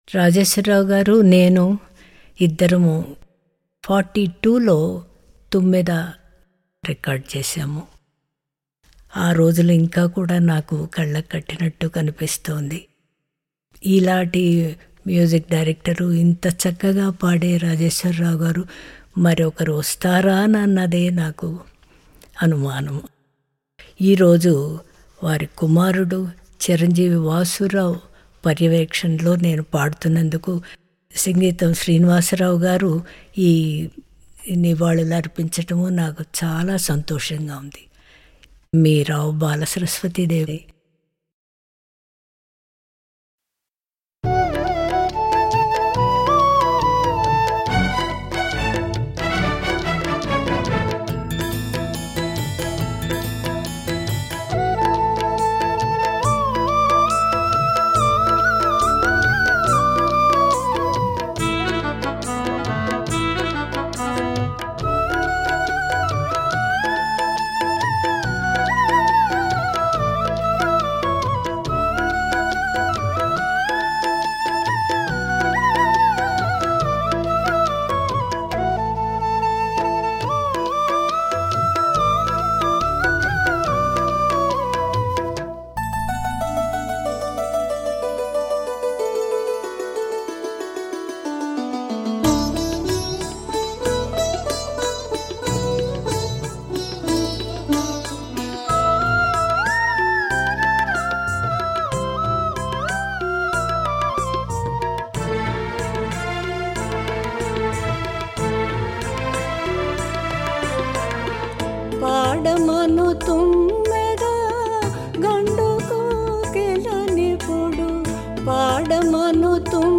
నిజానికి ఇదొక రీమిక్స్ పాట. అయితే